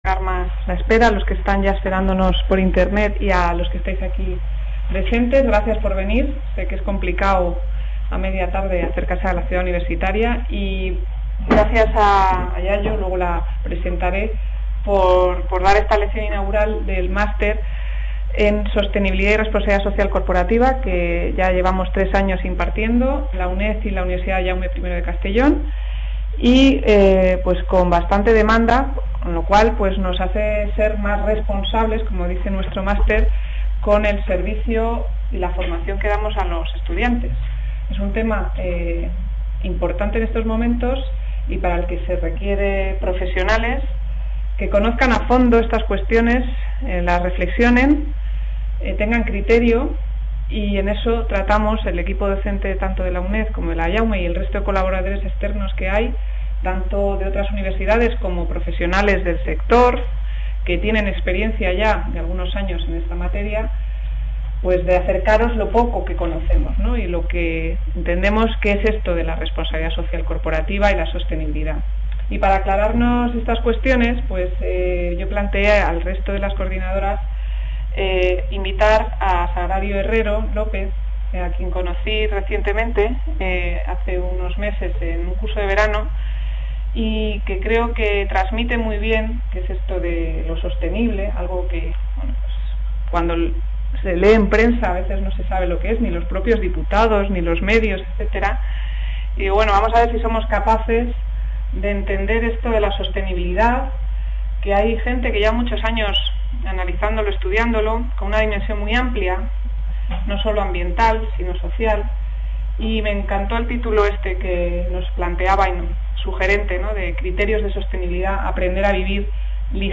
Lección inaugural